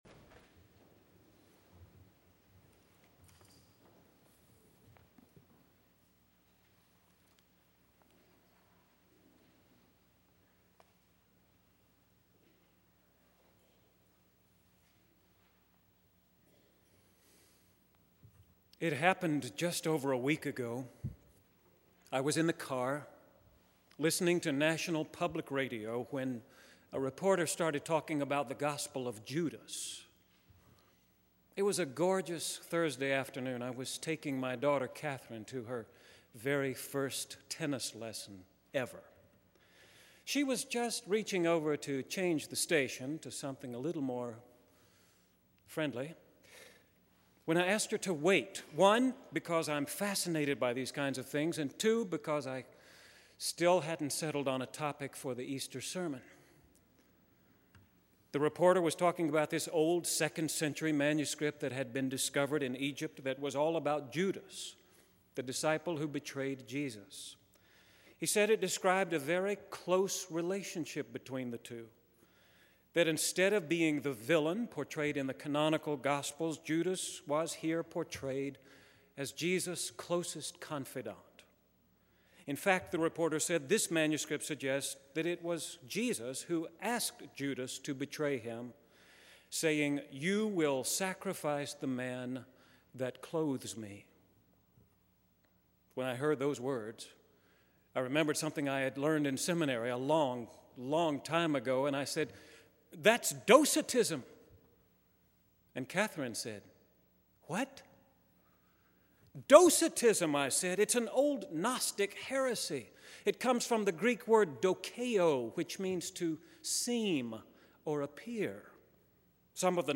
The recently recovered Gospel of Judas sets forth the old Gnostic heresy of docetism, suggesting that Christ put on the flesh of Jesus like a man might put on an overcoat. In this sermon I set forth the orthodox view of resurrection which is resurrection of the body, and not just the spirit.